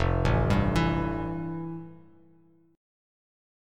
Listen to FM9 strummed